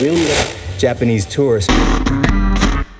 80BPM RAD5-R.wav